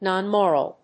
アクセント・音節nòn・móral